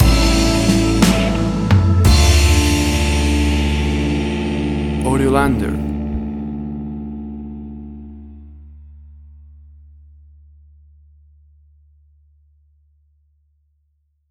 WAV Sample Rate: 16-Bit stereo, 44.1 kHz
Tempo (BPM): 60